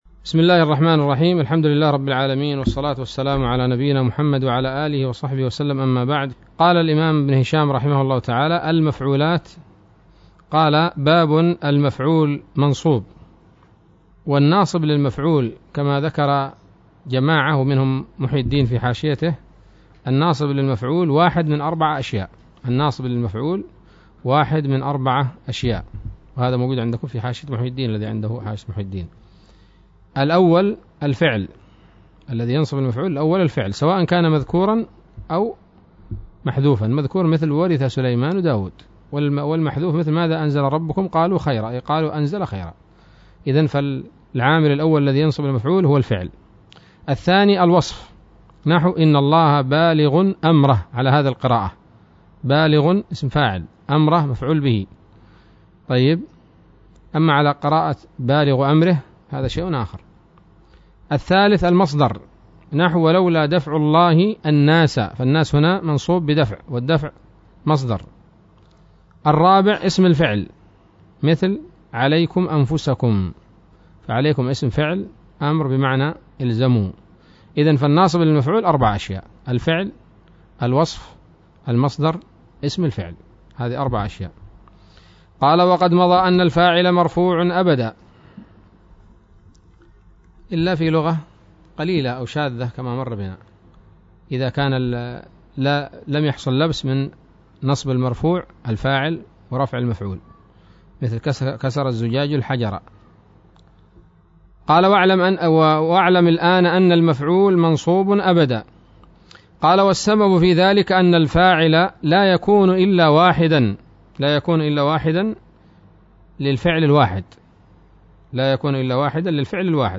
الدرس الثالث والثمانون من شرح قطر الندى وبل الصدى